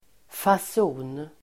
Uttal: [fas'o:n]